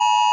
iPhone eas alarm short
iphone-eas-alarm-short.mp3